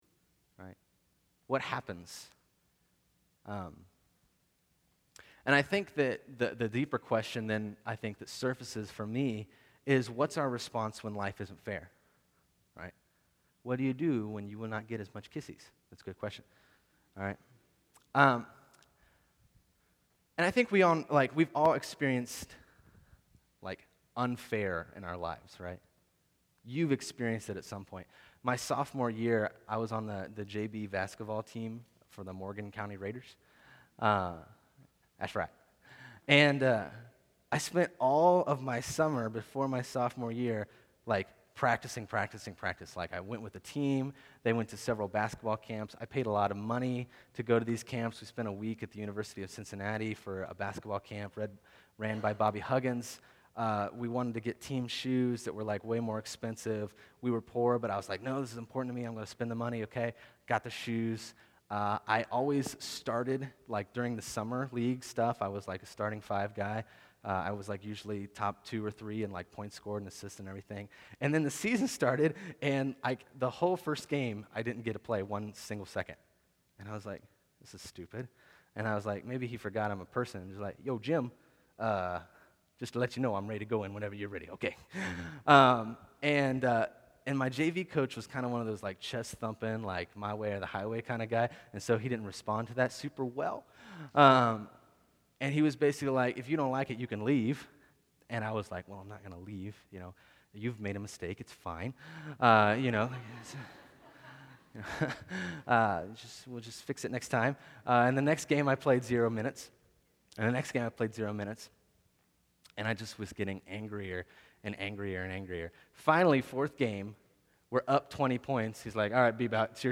Sermons - The Well